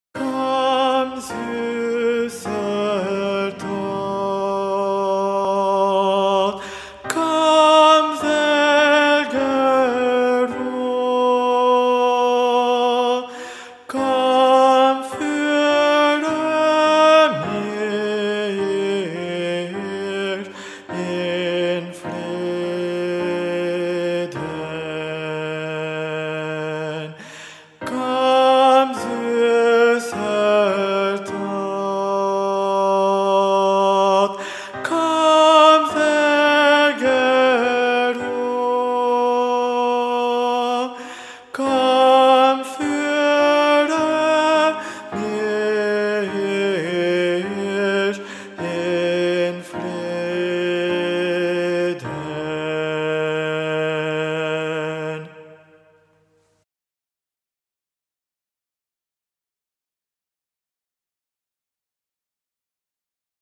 Versions chantées
Guide Voix Sopranos Mp 3